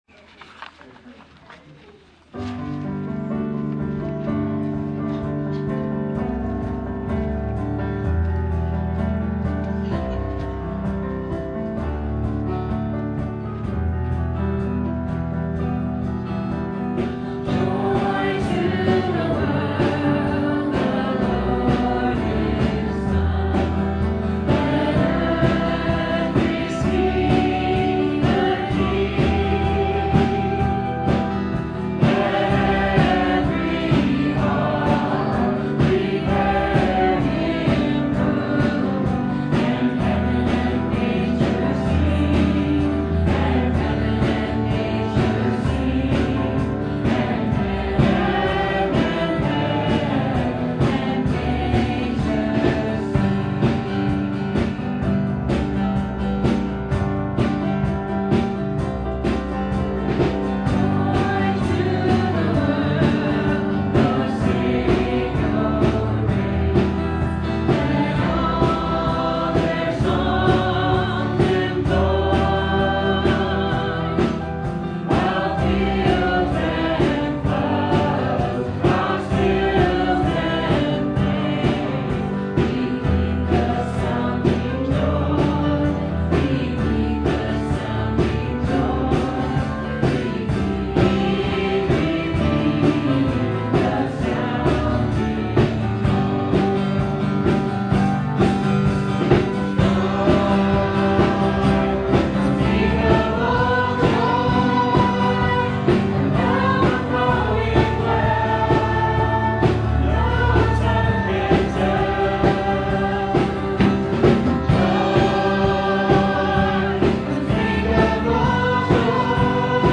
The Joyful closing of our recent worship service.